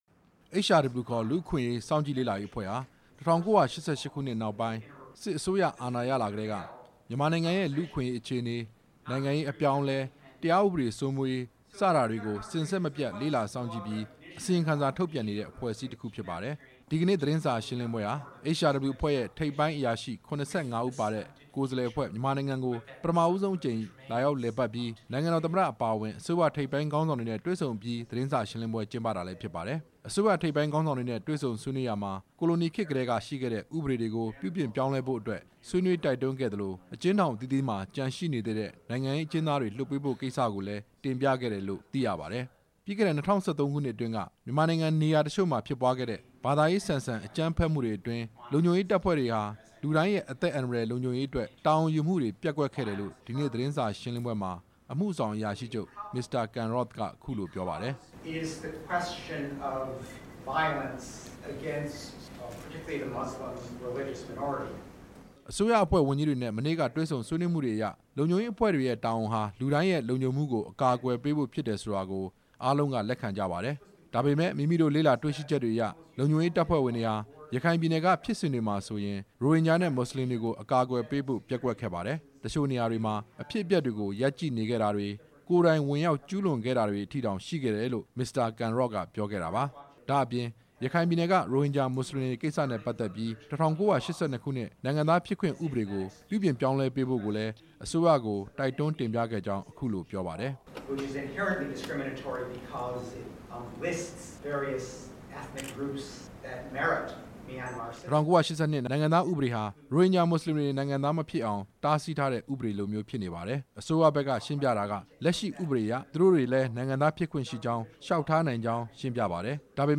ဒီနေ့  ရန်ကုန်မြို့ ကုန်သည်ကြီးများဟိုတယ် Traders Hotel မှာ ခရီးစဉ်နဲ့ပတ်သက်ပြီး သတင်းစာရှင်းလင်းပွဲ ကျင်းပရာမှာ HRW ရဲ့  အမှုဆောင်အရာရှိချုပ် Ken Roth က မြန်မာနိုင်ငံ အပြောင်းအလဲအပေါ် သူတို့ရဲ့သုံးသပ်ချက်ကို အခုလို ပြောခဲ့တာပါ။